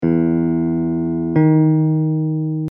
In the diagrams below, we are jumping from a note on an open string (any string will do) to another note on the same string.
Major Seventh = 5 ½  steps
major-7th.mp3